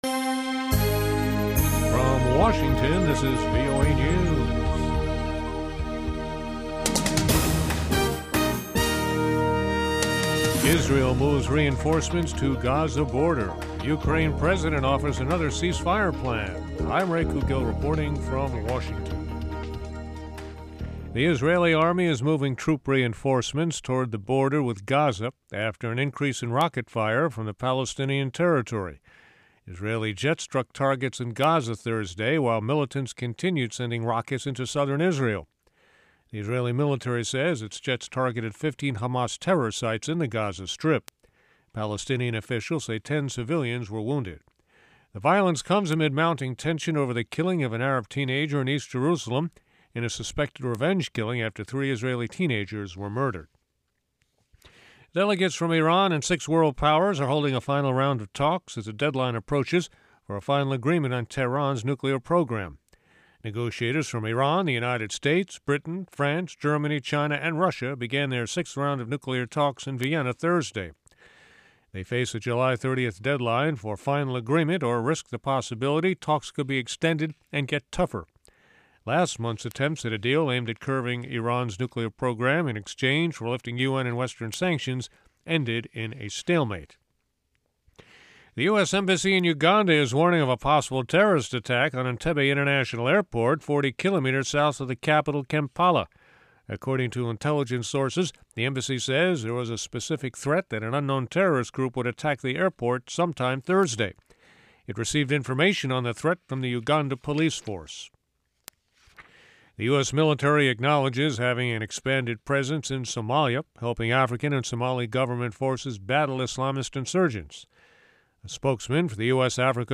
Urdu Headlines